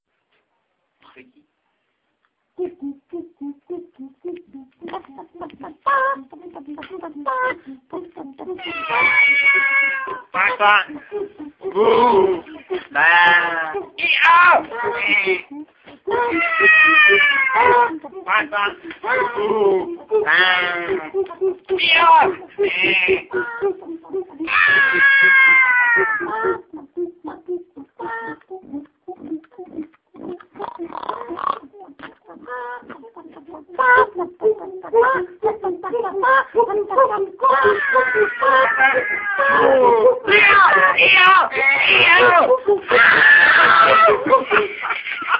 Sta�ení: Veselá zví�ádka - zvuky jiho�eského statku Upadl - kánon